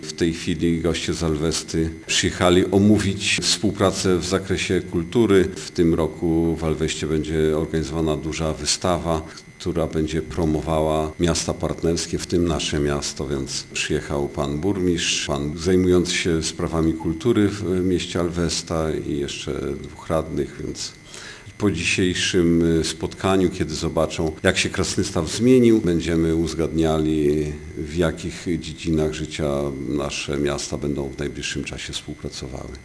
Obecna wizyta to spotkanie robocze - informuje burmistrz miasta Andrzej Jakubiec: